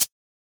Closed Hats
edm-hihat-52.wav